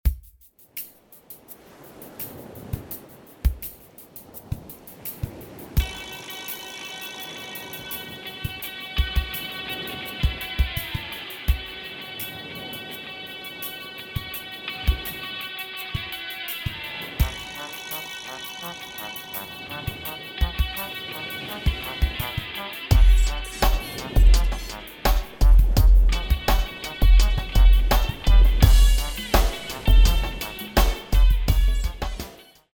10 Music tracks in various dance styles.
Warm-up, Cool Down, Modern, Jazz, Hip Hop